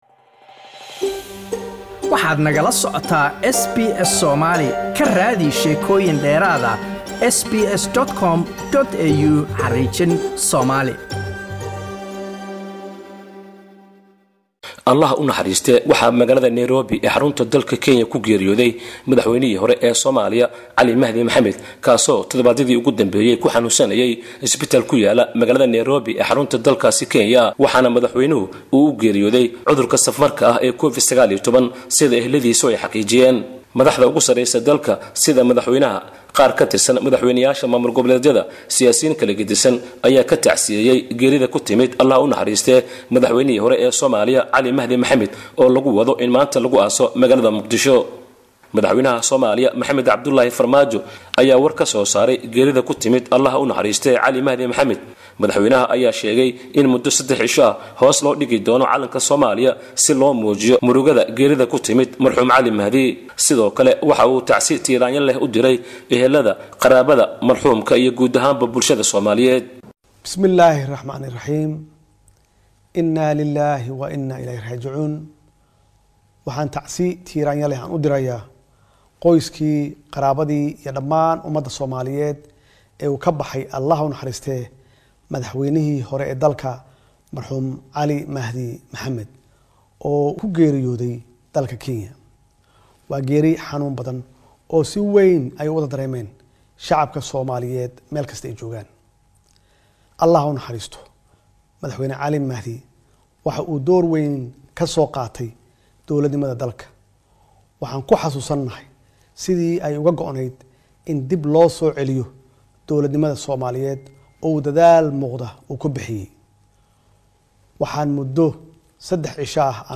Ehelada Madaxwene Cali Mahdi Mohamed oo xaqiijiyay inuu u geeriyooday xanuunka COVID-19 iyadoo madaxda dalka tacsi tiiraanyo leh u direen qoyskii marxuun Cali Mahdi Maxmed. Warbixin kooban oo ku saabsan geerida madaxweyne Cali Mahdi.